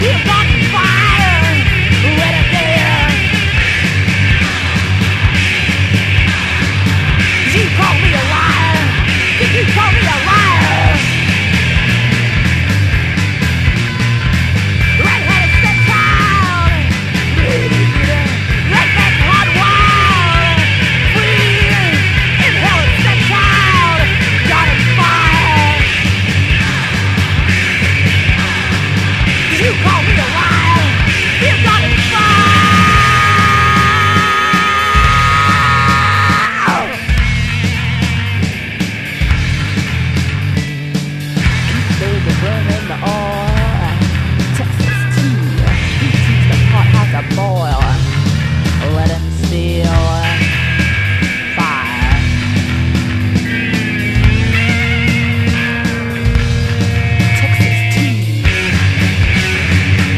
現行サイケデリック・ガレージ・パンク！
ギラギラのノイズ・ギターとドロッとした歌い口が最高なロックンロール・ナンバー
ジャキジャキ剃刀ギターが咆える
クセのある歌い方にパンク・スピリットを感じる幽玄サイケ
エスニックなメロディで迫る